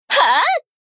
贡献 ） 分类:蔚蓝档案语音 协议:Copyright 您不可以覆盖此文件。
BA_V_Aru_Newyear_Battle_Shout_1.ogg